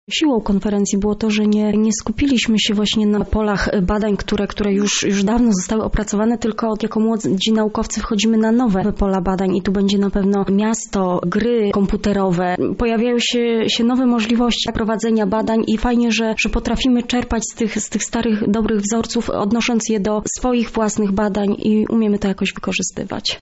Konferencję podsumowuje doktorantka